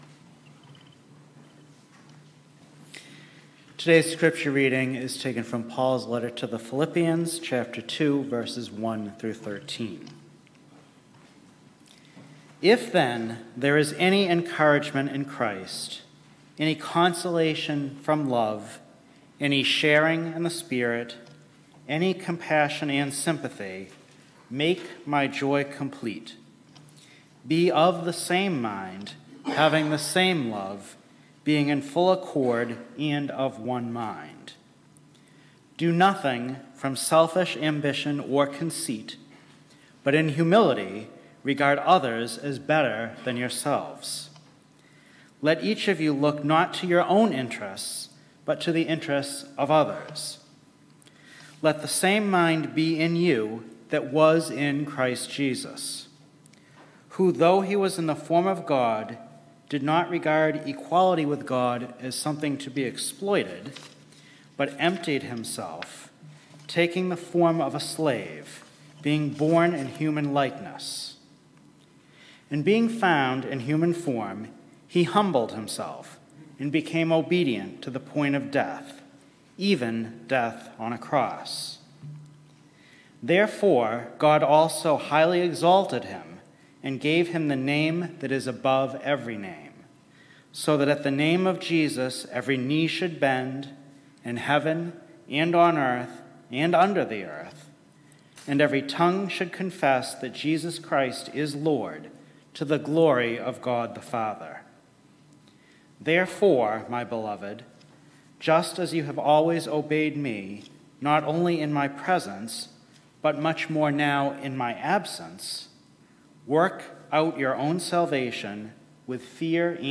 Scripture-Reading-and-Sermon-Aug-7-2022.mp3